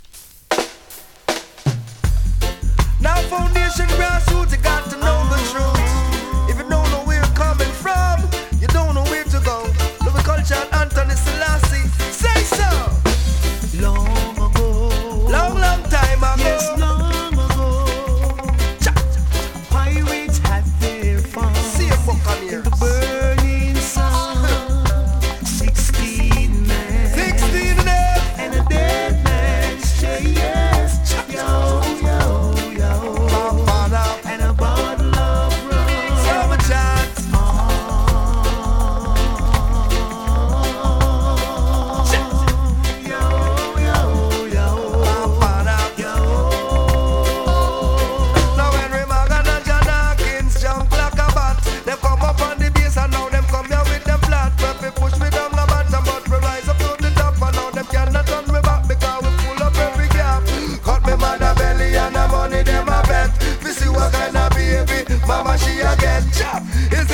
2026 NEW IN!! DANCEHALL!!
スリキズ、ノイズかなり少なめの